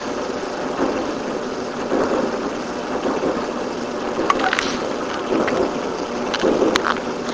SOUNDS " 烘干机
描述：烘干机